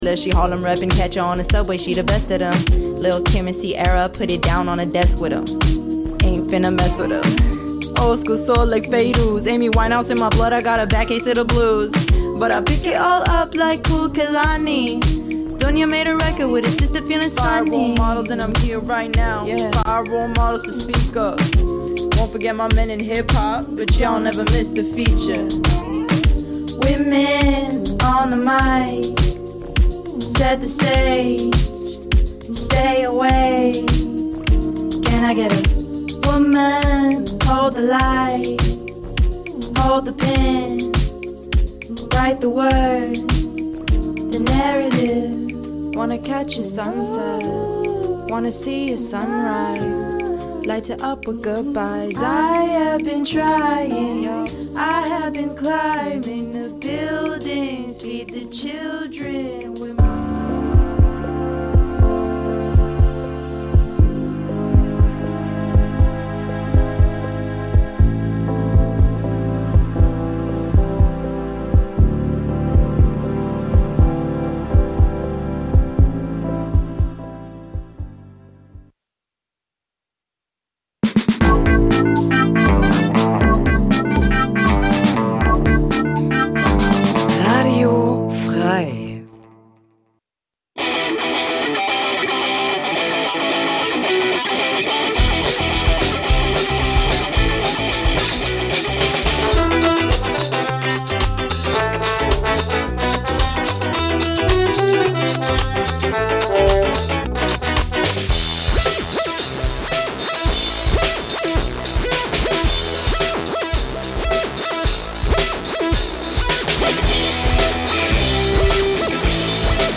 Jeden Donnerstag stellen wir euch regionale Musik vor und scheren uns dabei nicht um Genregrenzen. Ob Punk, Rap, Elektro, Liedermacher, oder, oder, oder � � wir supporten die Th�ringer Musikszene. Wir laden Bands live ins Studio von Radio F.R.E.I. ein, treffen sie bei Homesessions oder auf Festivals.
Regionale Musik Dein Browser kann kein HTML5-Audio.